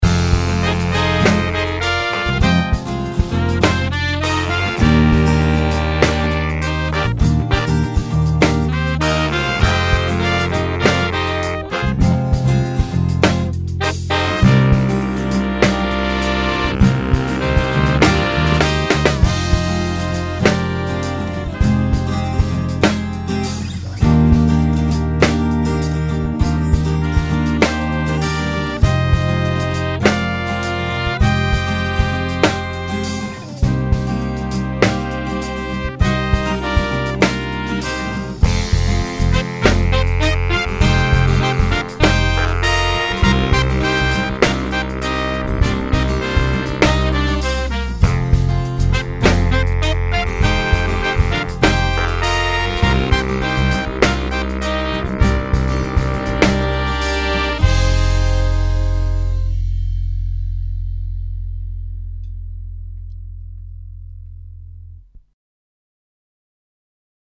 Featuring 109 instrumental loops and musical phrases.
This pack features full horn ensembles as well as individual loops for sax, trumpet, acoustic guitar, bass, and drums, as well as a selection of solo sax loops.
Loops are recorded at 100bpm in the key of C, with bonus sax solos recorded in A.